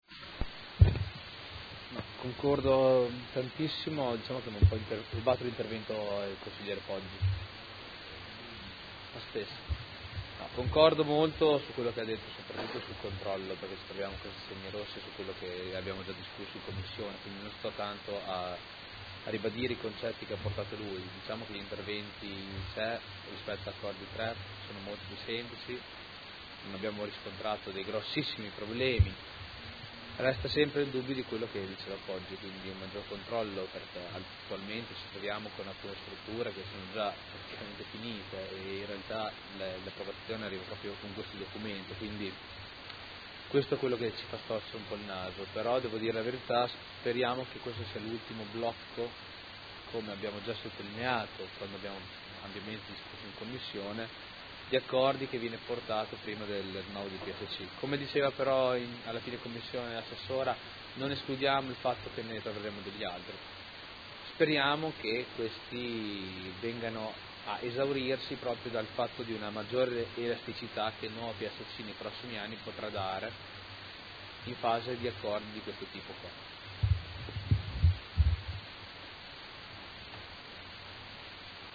Seduta del 22 ottobre. Proposta di deliberazione: Variante al Piano operativo comunale (POC) e al Regolamento urbanistico edilizio (RUE) – Controdeduzioni e approvazione ai sensi degli artt 33 e 34 della Legge regionale 20/2000 e s.m. Dibattito